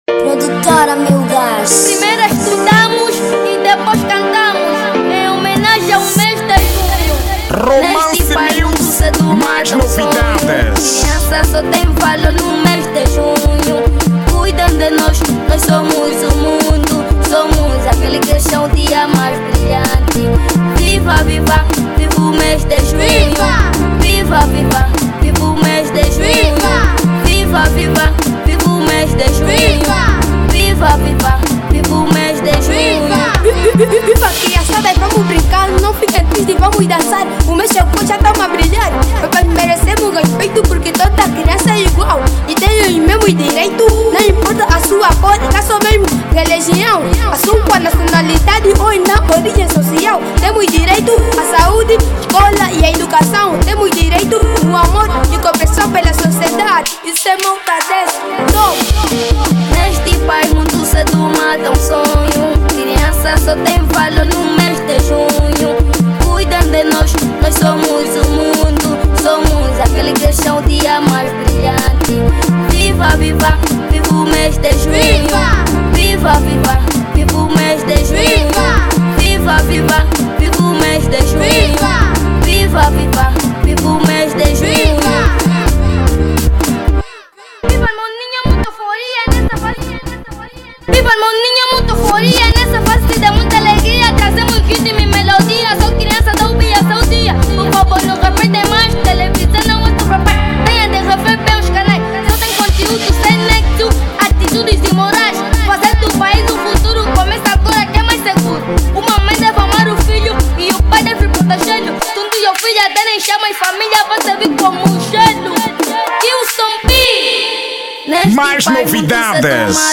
Estilo: Afro House